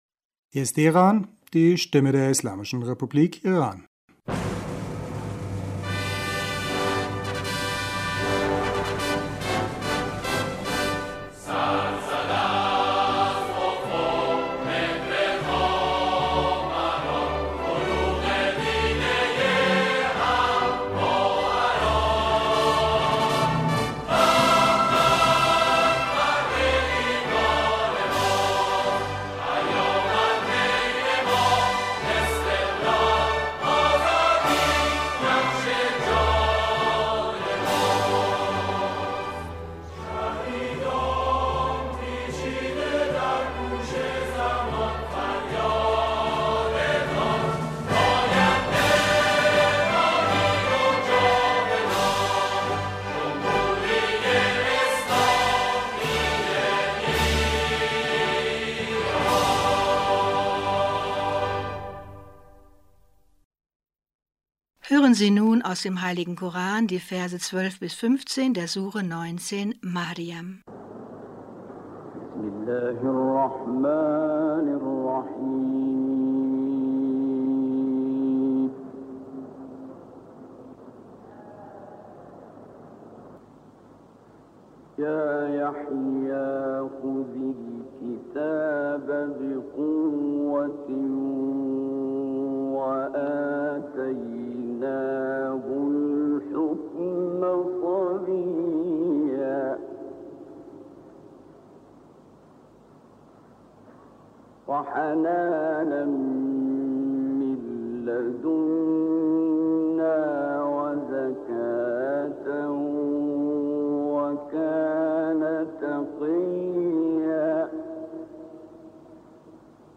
Nachrichten vom 23. Oktober 2022